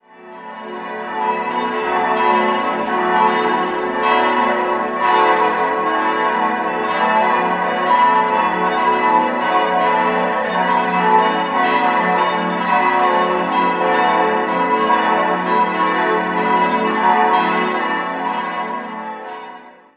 Innenansicht liegt leider nicht vor. 5-stimmiges Geläute: fis'-a'-h'-cis''-e'' Die Glocken wurden 1965 von der Gießerei Mark in Brockscheid gegossen.